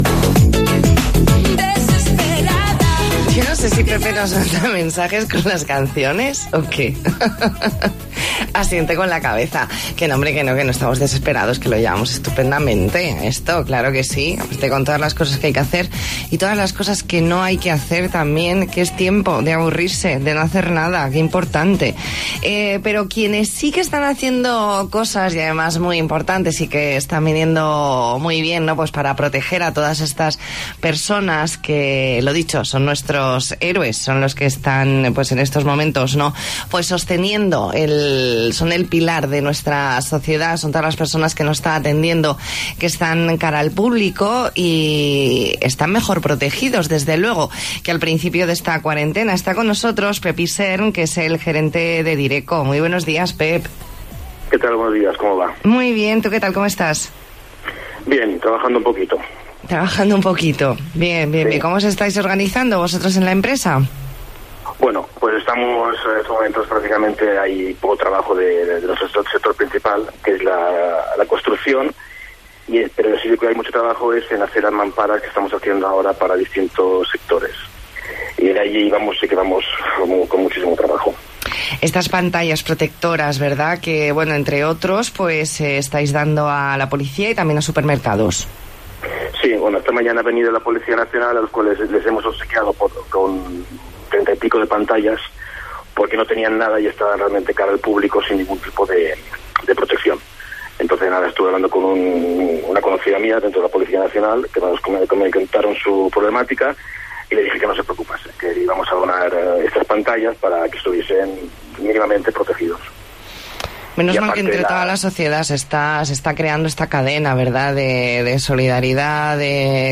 Entrevista en La Mañana en COPE Más Mallorca, viernes 27 de marzo de 2020.